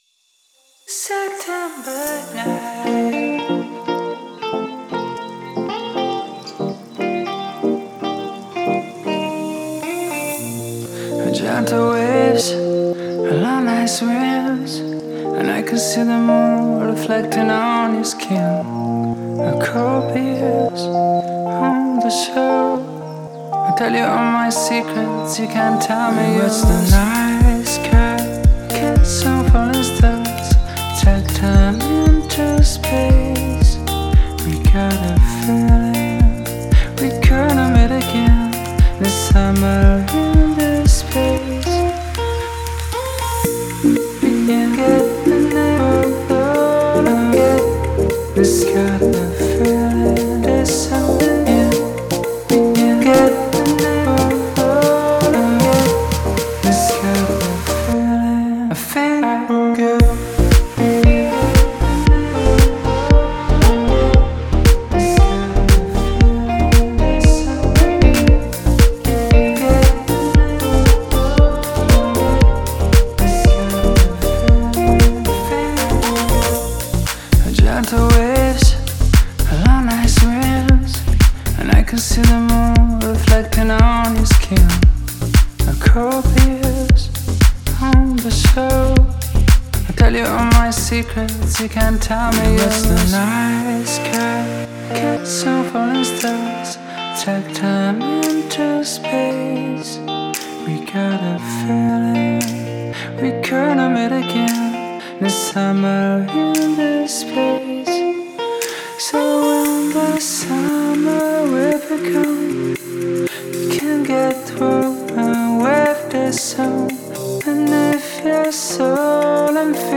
это атмосферная композиция в жанре chill-out и electronic